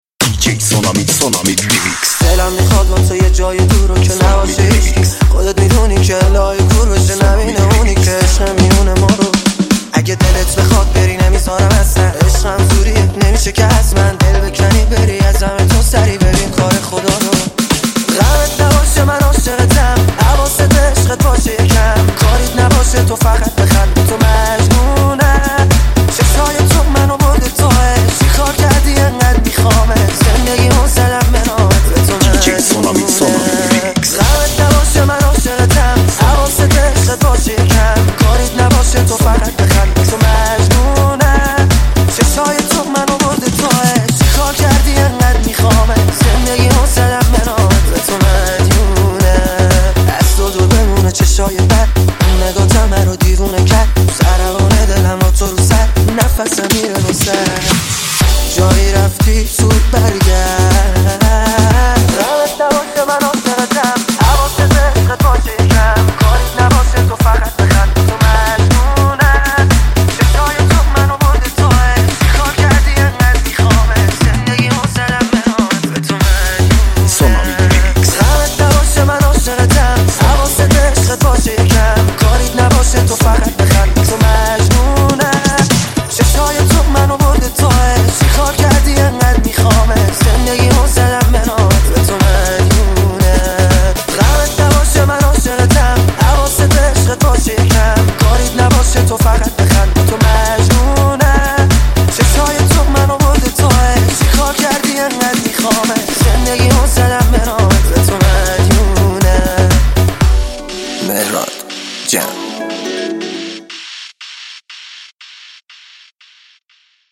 دانلود آهنگ شاد با کیفیت ۱۲۸ MP3 ۲ MB